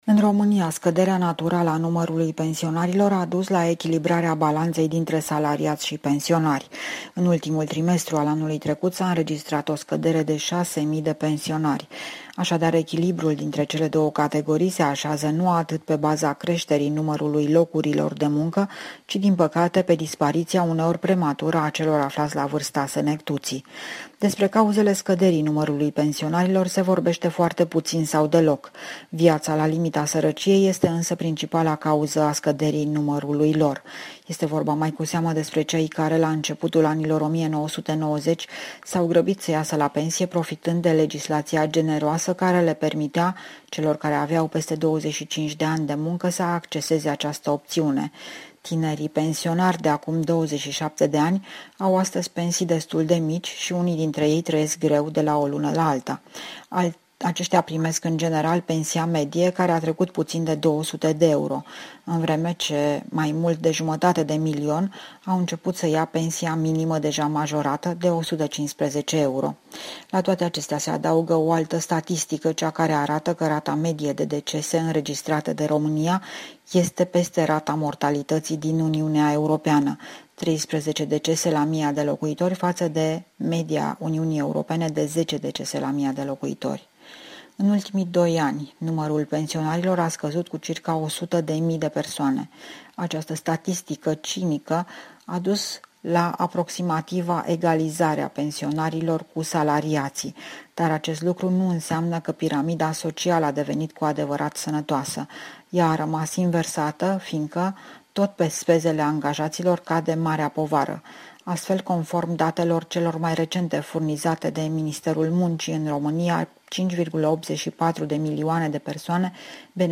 Corespondența zilei de la București